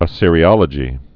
(ə-sîrē-ŏlə-jē)